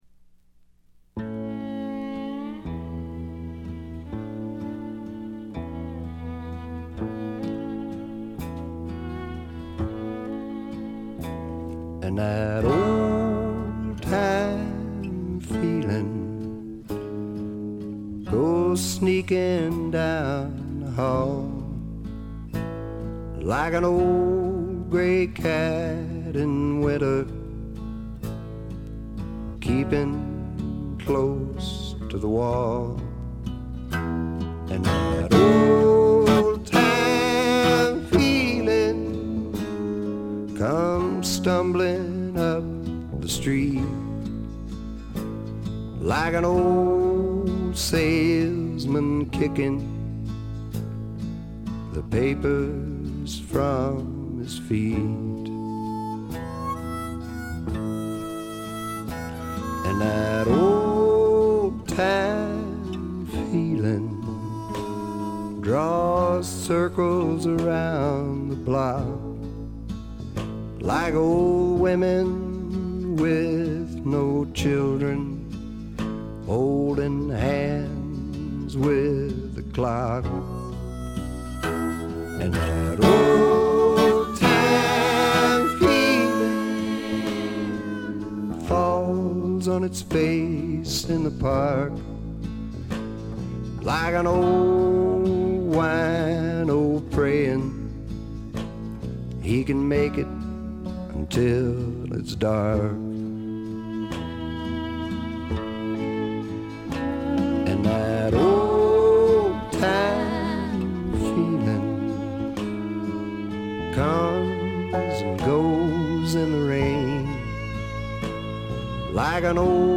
ほとんどノイズ感無し。
朴訥な歌い方なのに声に物凄い深さがある感じ。
試聴曲は現品からの取り込み音源です。
Guitar, Vocals